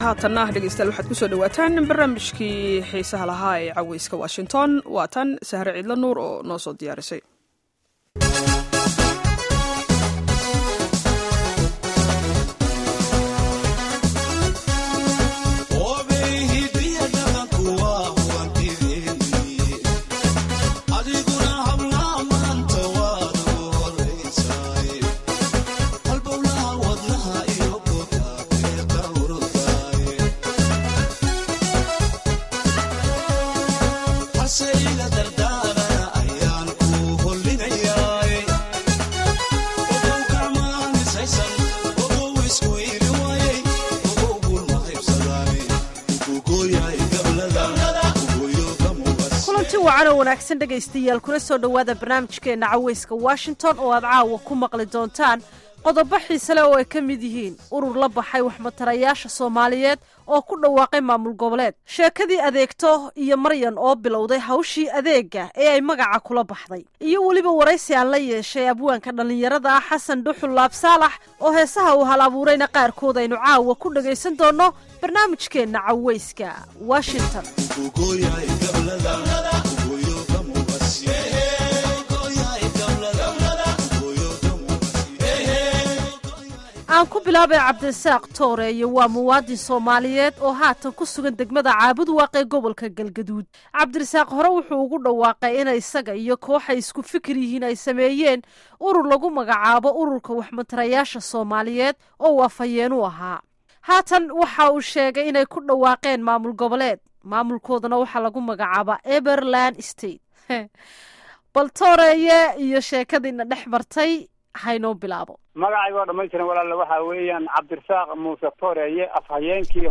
Barnaamijka Caweyska Washington ee toddobaad waxaan idiinku haynaa qodobo kale duwan oo uu ka mid yahay wareysi aan la yeelanaya Abwaanka